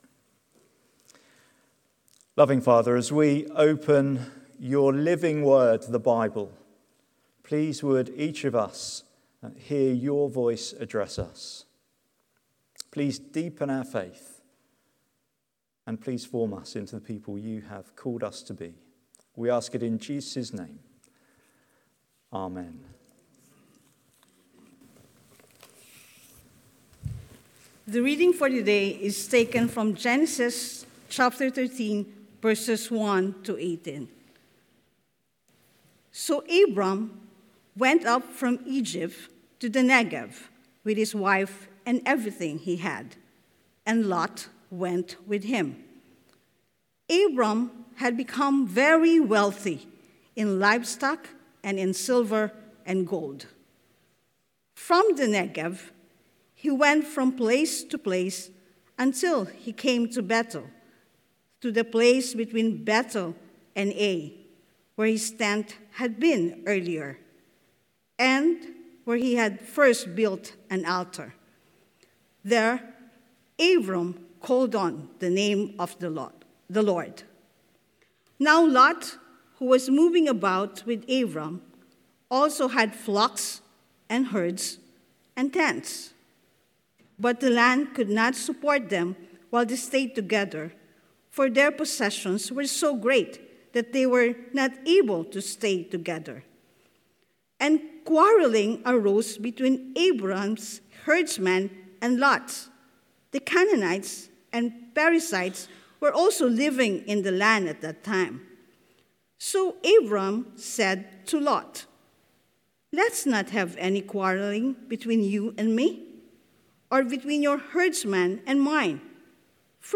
Sermon Transcript